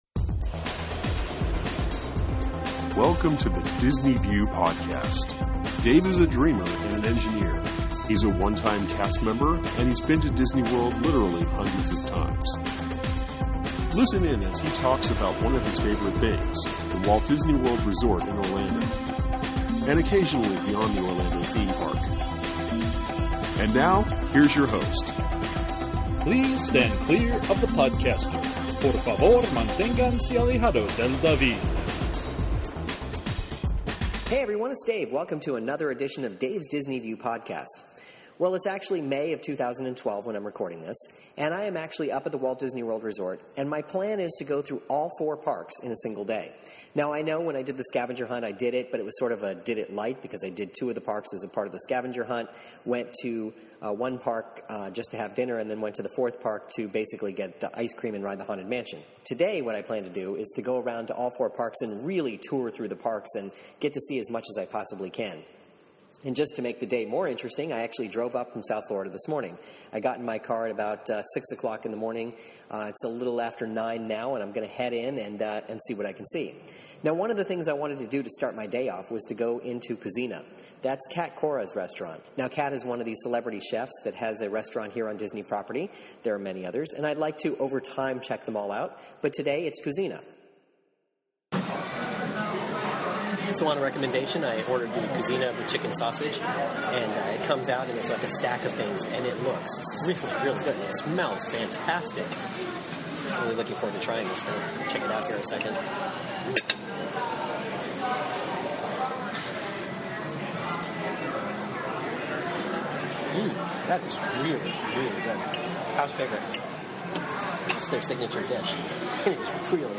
Back in May, I took a trip to the parks, and started a 4-park adventure in a single day. On this episode you'll hear me as I start out at Kouzzina on the Boardwalk, and then I head into Epcot and over to the Studios.